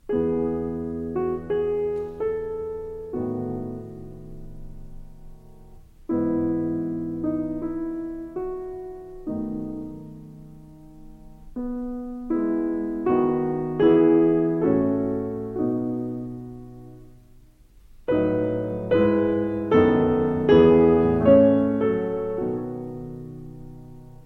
Uitgevoerd door Alfred Brendel.